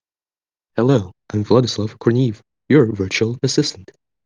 critical-robot-failure.wav